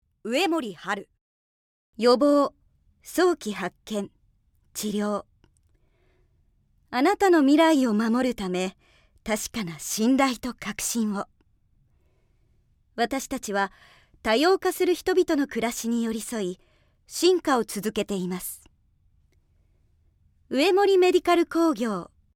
◆医療機器メーカーのCM◆